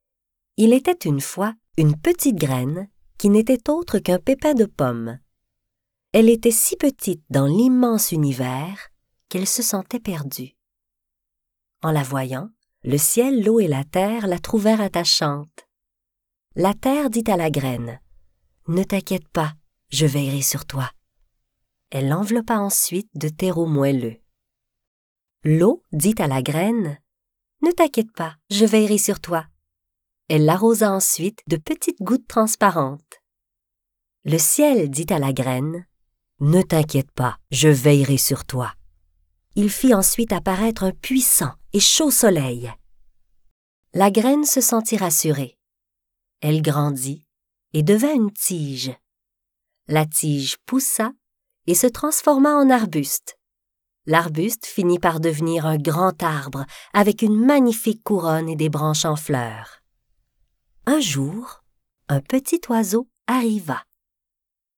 livre audio jeunesse  – ton doux, débit lent